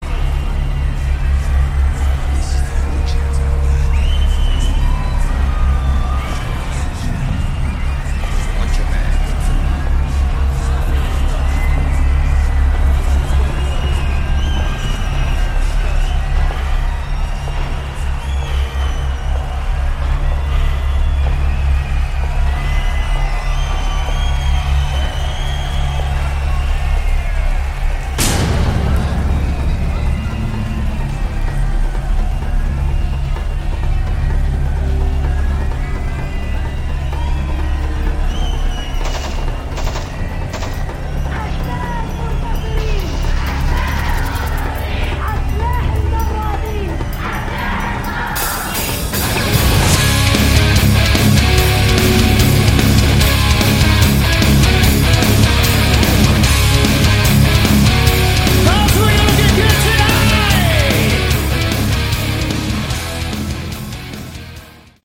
Category: Hard Rock
Vocals
Guitars
Bass
Drums